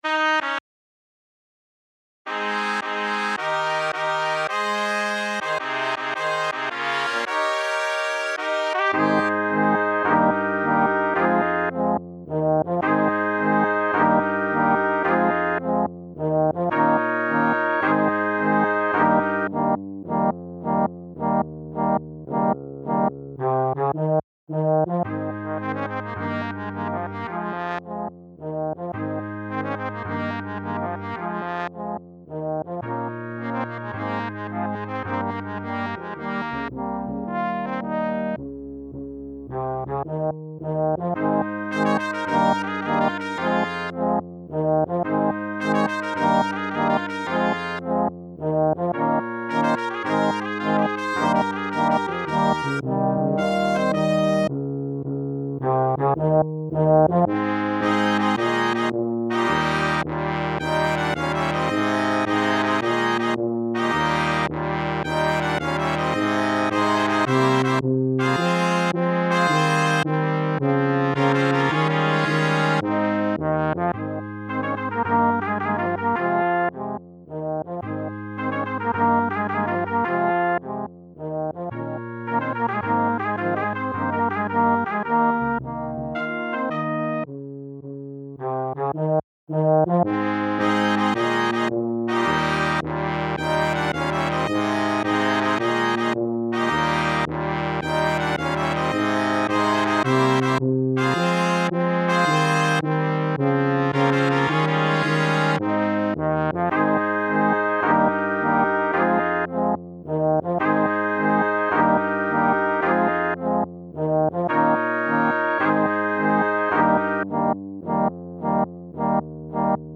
Gattung: für variables Ensemble
Besetzung: Ensemblemusik für 7 Blechbläser